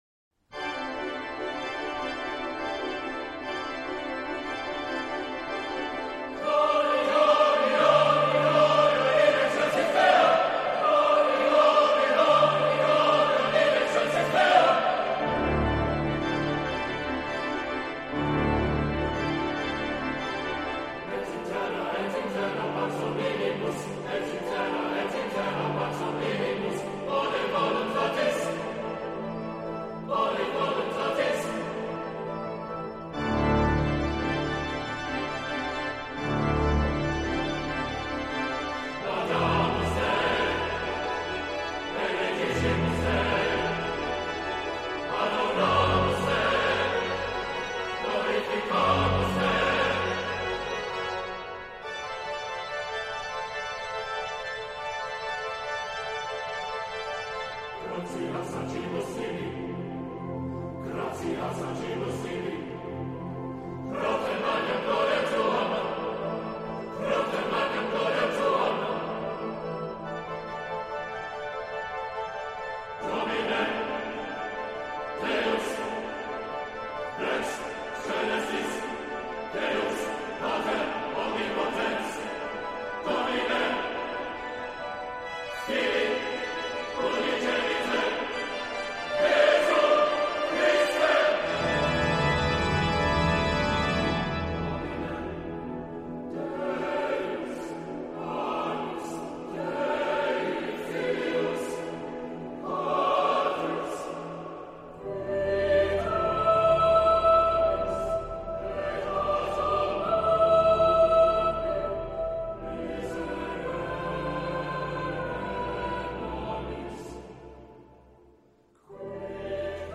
Genre-Style-Form: Sacred ; Mass
Mood of the piece: contrasted
Type of Choir: SATB (div)  (4 mixed voices )
Instrumentation: Organ  (1 instrumental part(s))
Tonality: G tonal center